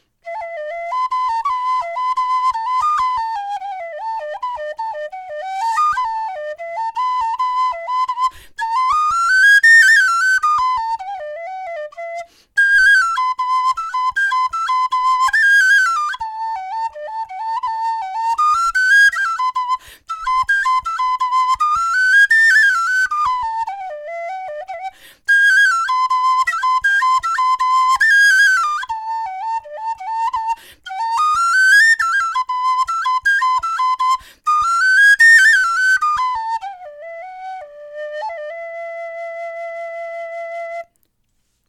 Diese Tin-whistle von Dixon ist aus schwarzem Kunststoff gefertigt.
Sie hat einen angenehm weichen Klang und ist leicht über zwei Oktaven spielbar.
Stimmung D
Klangbeispiel Dixon Tinwhistle
dixon-tinwhistle-d-melodie.mp3